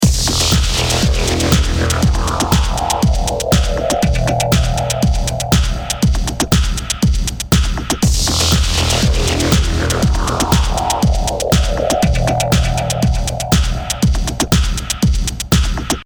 We will use it to add more bite and strength to our drums, as well as giving them more depth and space.
A strong boost of 6dB at 50Hz lends the drums some deep low end energy, while a small dip at around 2kHz takes out unnecessary boxyness.
And here’s what the track sounds like with Grasso engaged:
Grasso-Industrial-Drums-WET.mp3